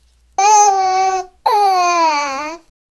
Cute baby panda voice
cute-baby-panda-voice-xnurgsz5.wav